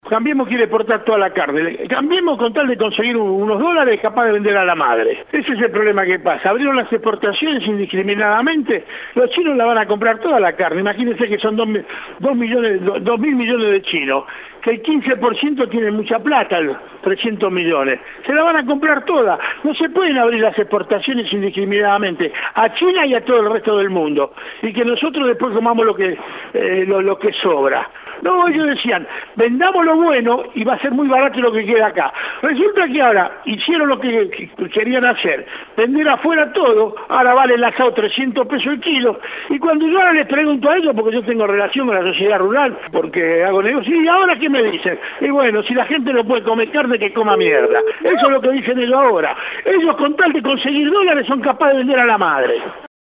En diálogo con Radio EME, Samid aseguró que «el gobierno es capaz de vender a la madre por unos dólares».